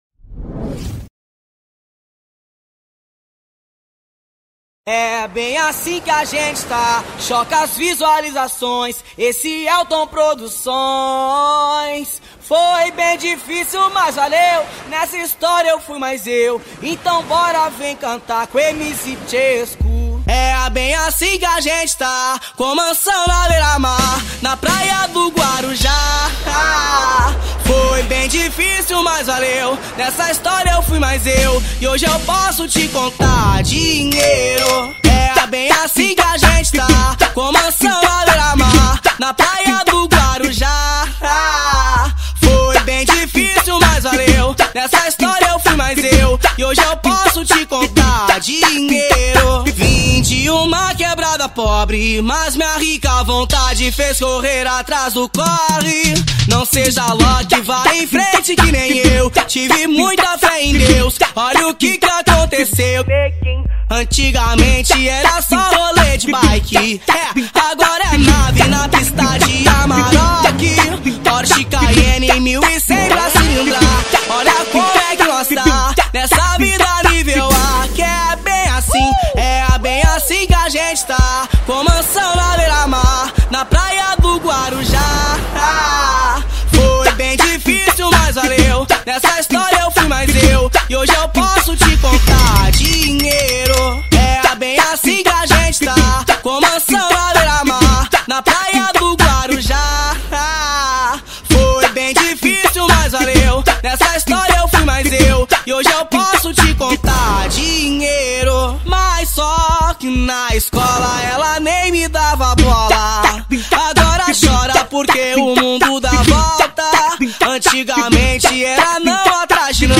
2024-12-17 14:46:24 Gênero: Funk Views